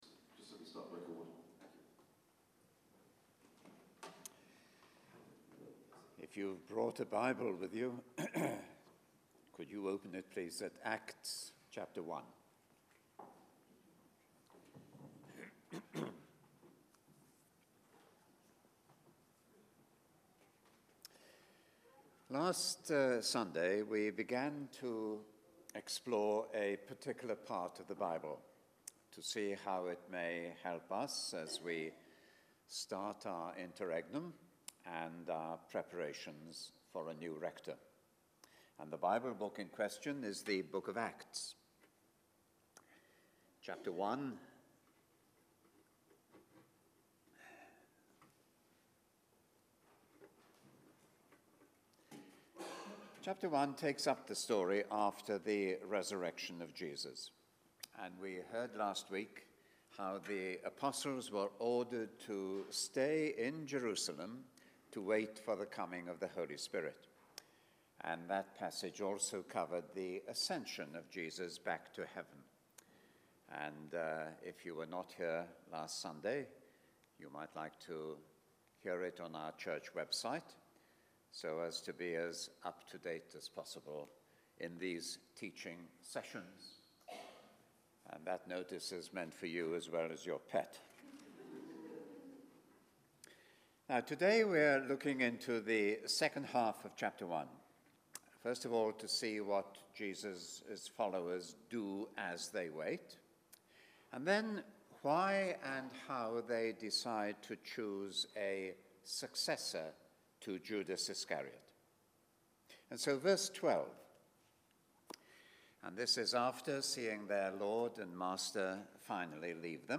Listen to Sermons : St Marys